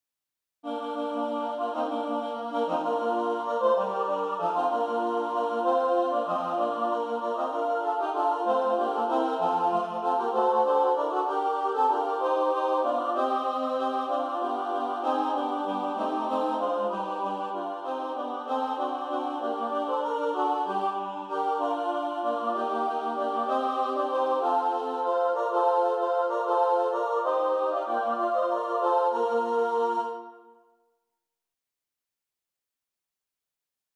SSAA Choir setting